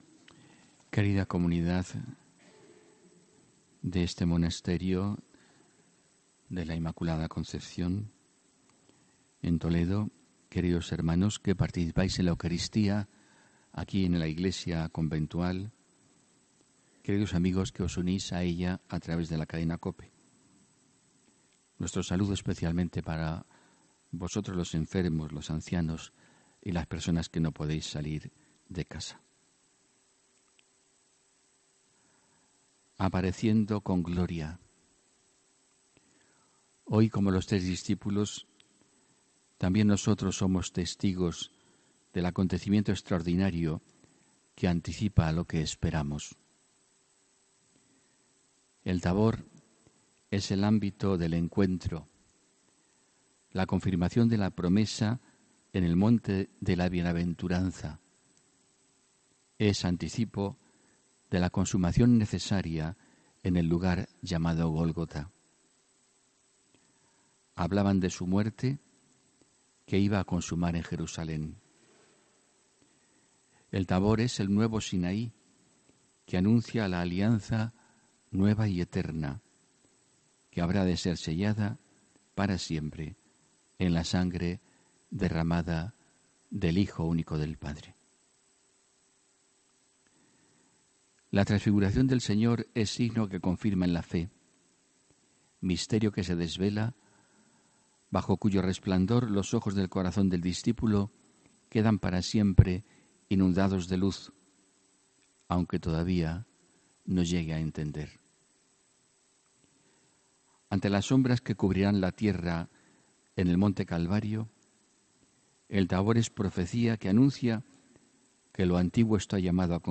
HOMILÍA 17 MARZO 2019